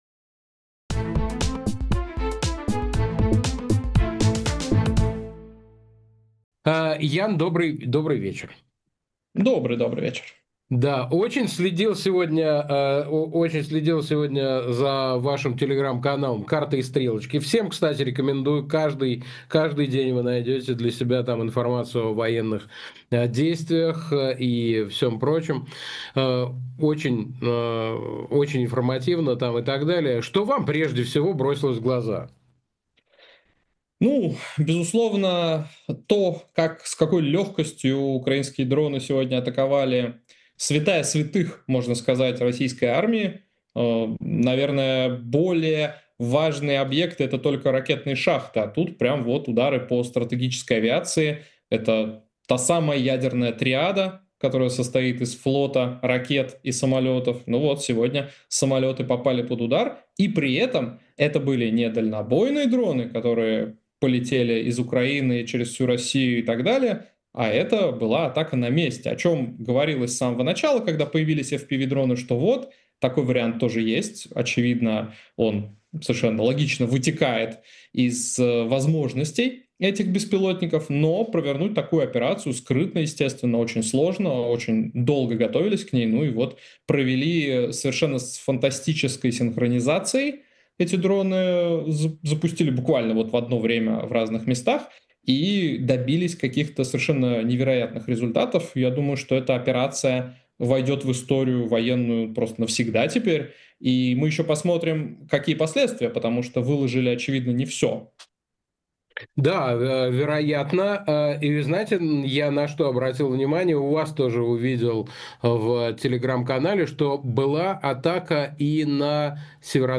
Фрагмент эфира от 2 июня.
военный эксперт
Интервью 3 июня 2025 Операция «Паутина»: чем ответит Россия?